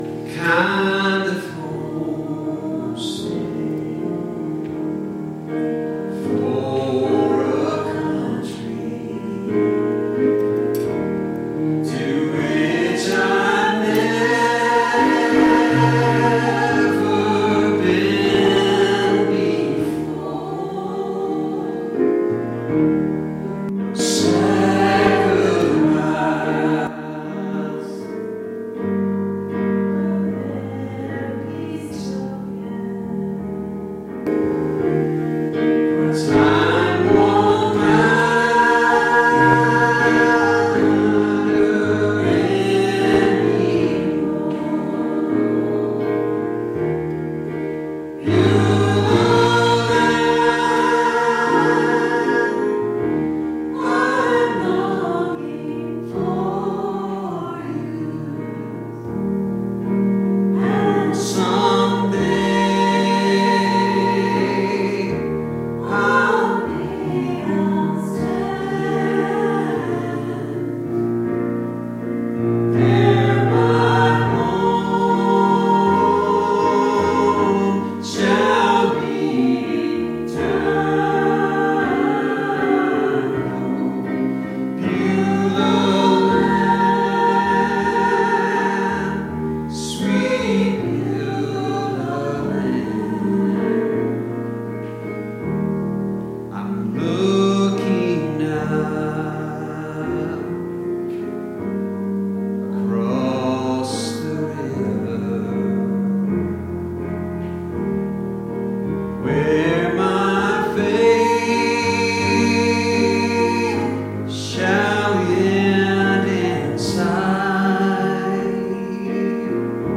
Processional